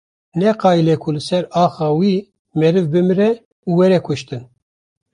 Pronúnciase como (IPA)
/kʊʃˈtɪn/